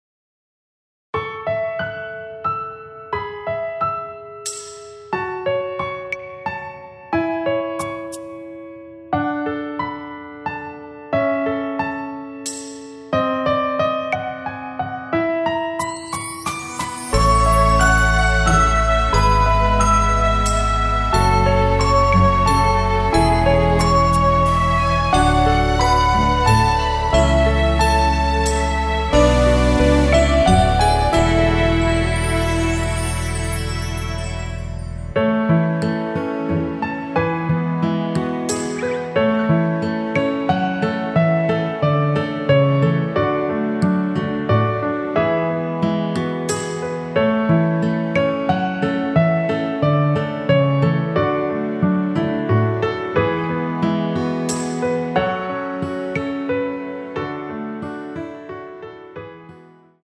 원키에서(+7)올린 MR입니다.
앞부분30초, 뒷부분30초씩 편집해서 올려 드리고 있습니다.
중간에 음이 끈어지고 다시 나오는 이유는